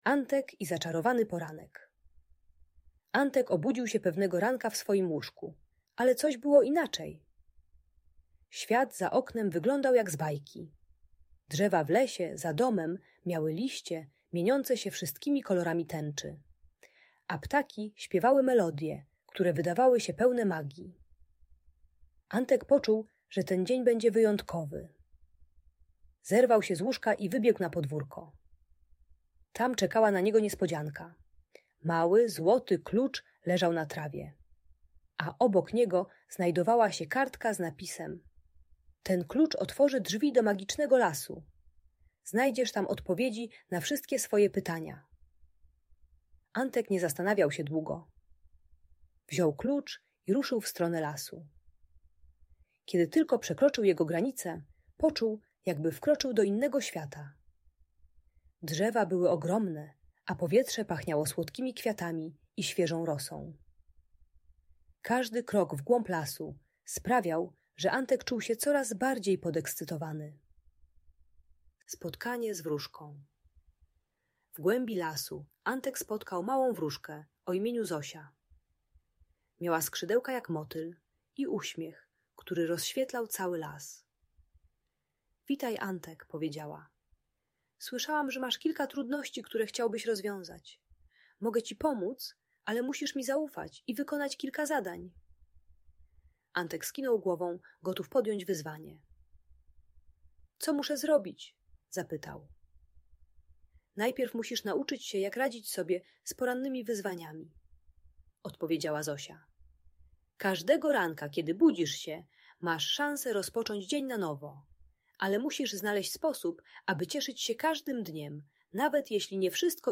Antek i Zaczarowany Poranek - Szkoła | Audiobajka